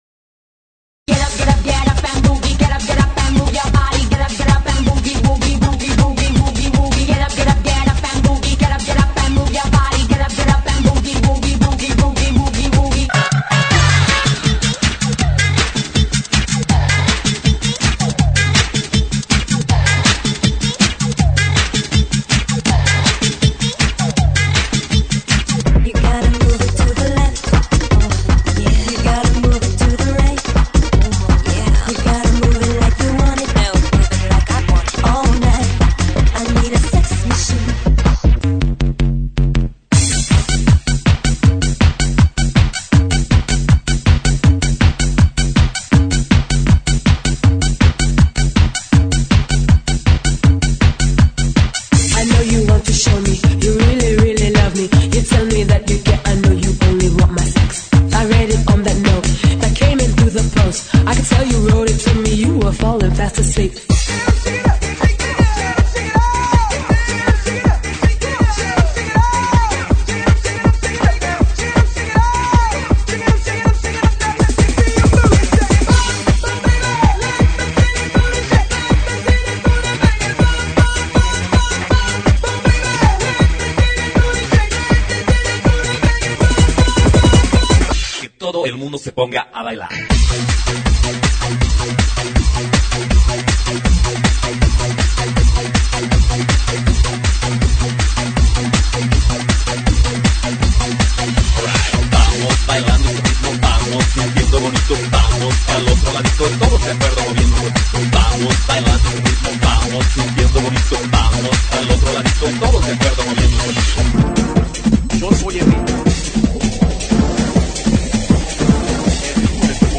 GENERO: DANCE – RETRO
DANCE RETRO